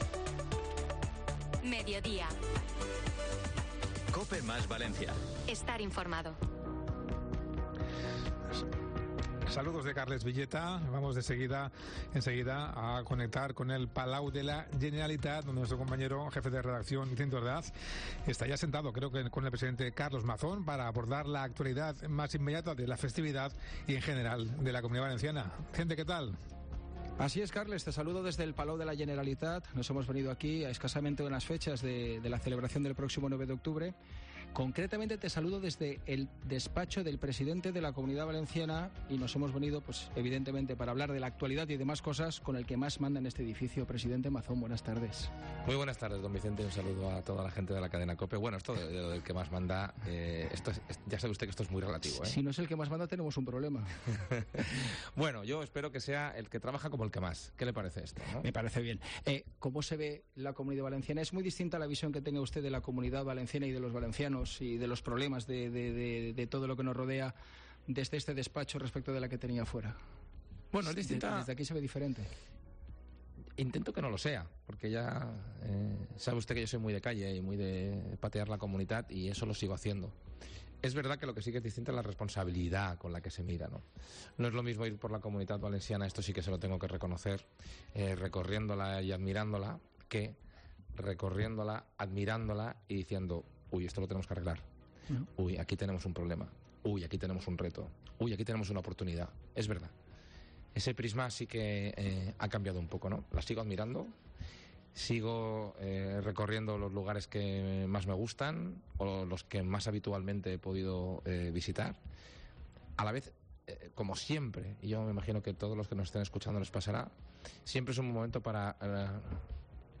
En vísperas del 9 d’Octubre el presidente de la Generalitat, Carlos Mazón, ha atendido a Mediodía COPE desde su despacho en el Palau de la Generalitat.